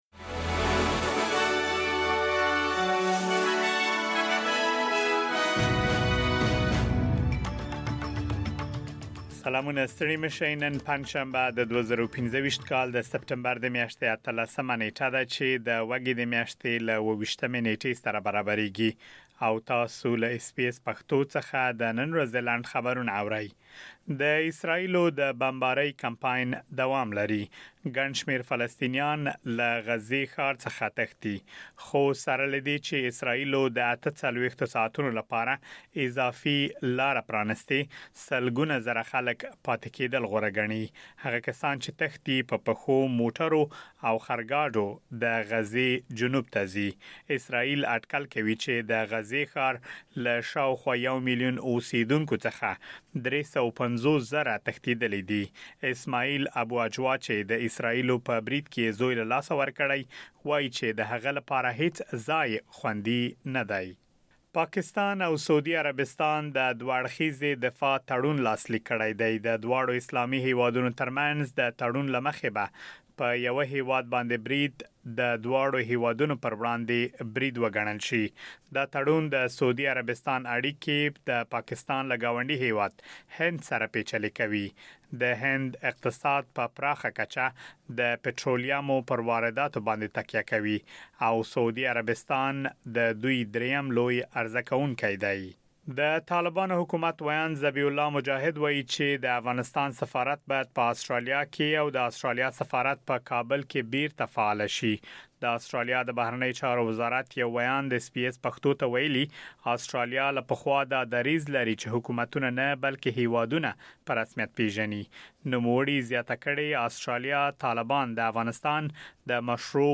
د اس بي اس پښتو د نن ورځې لنډ خبرونه |۱۸ سپټمبر ۲۰۲۵